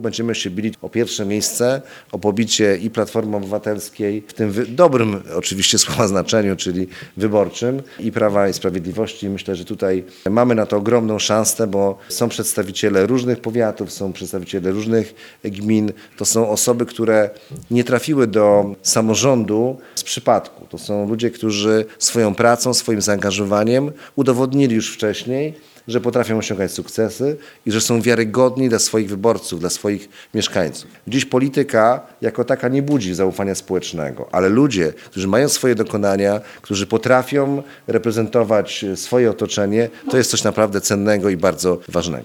Gościem specjalnym konwencji był prezydent Szczecina Piotr Krzystek, lider Koalicji Samorządowej.